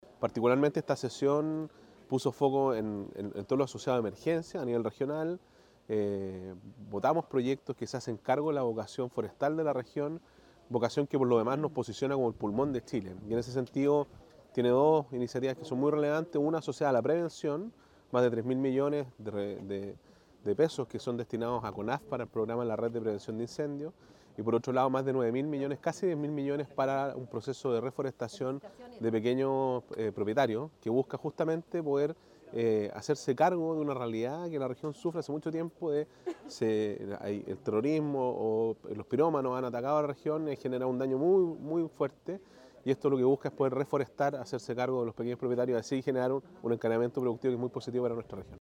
El gobernador Sergio Giacaman explicó que dichos proyectos se hacen cargo de la vocación forestal de la región, la que nos posiciona como “el pulmón de Chile”.